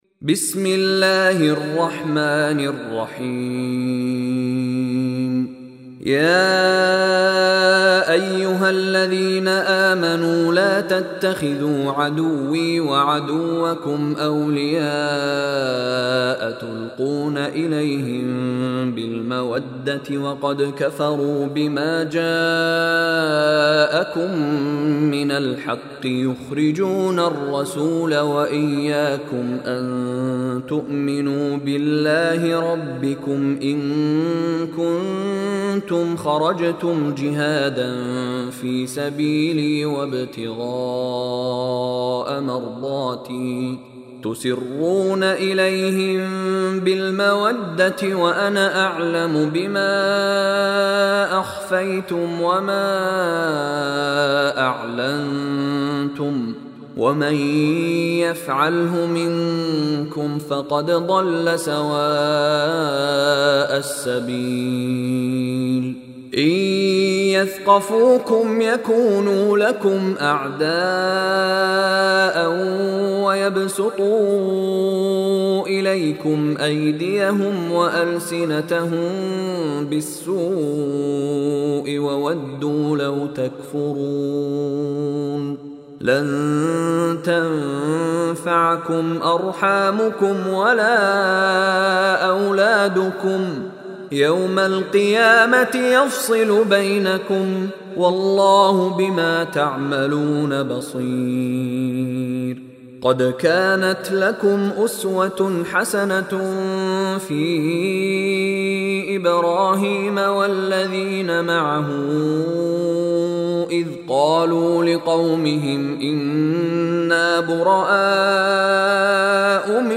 Surah Al-Mumtahinah Recitation by Mishary Rashid
Surah Al-Mumtahinah is 60th chapter of Holy Quran, listen online mp3 tilawat / recitation in the voice of Sheikh Mishary Rashid Alafasy.